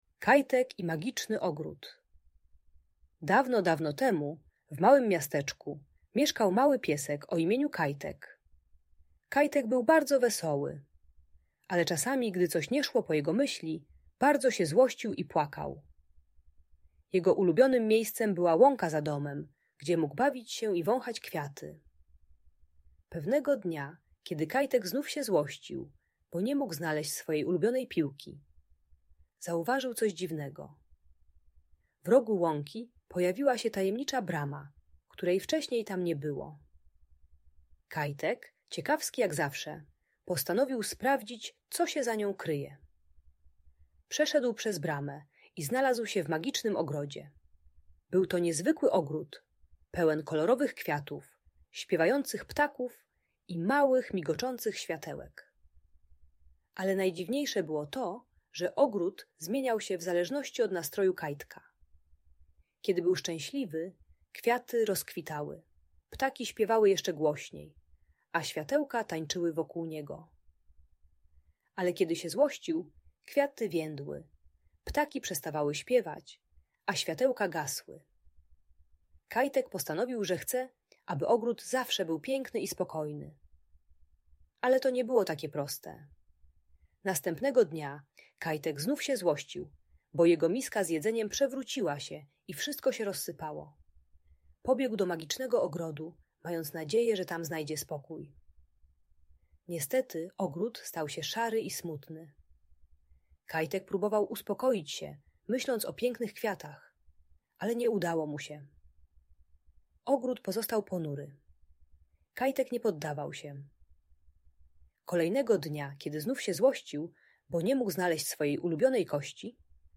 Kajtek i Magiczny Ogród - Audiobajka